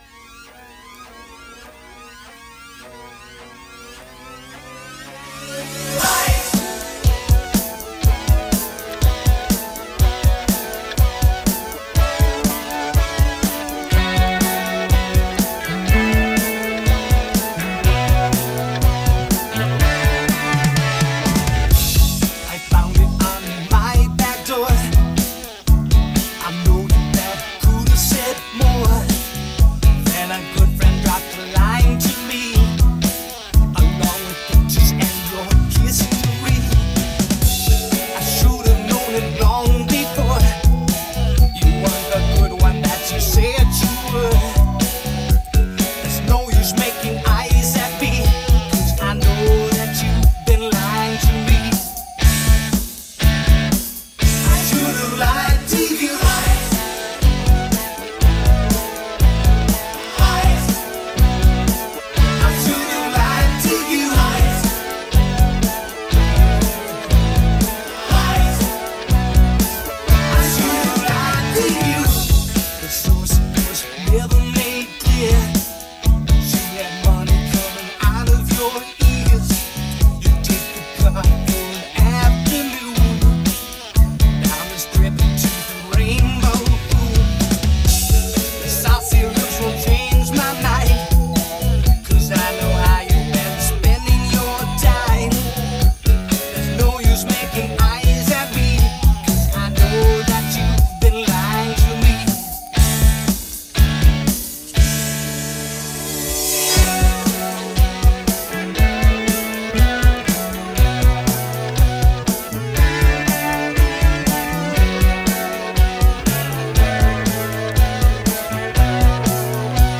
Hier in besserer Qualität.